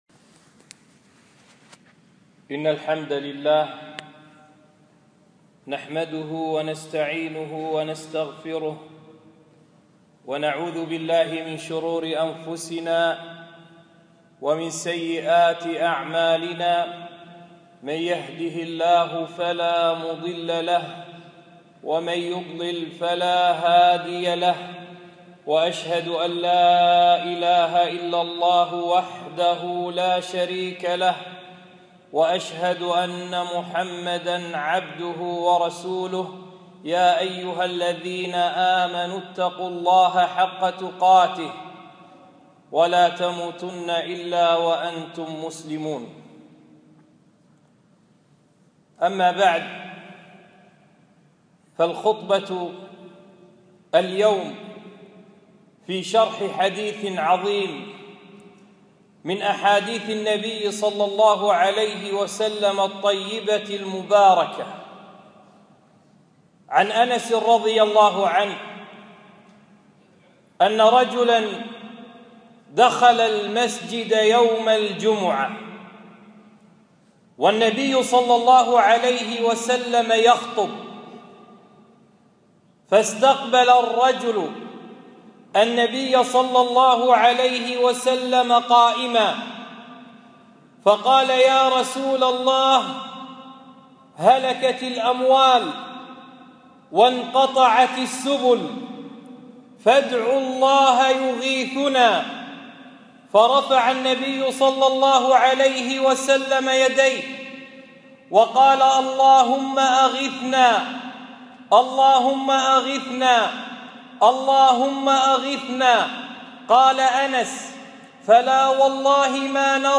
خطبة - أن يغيثهم - دروس الكويت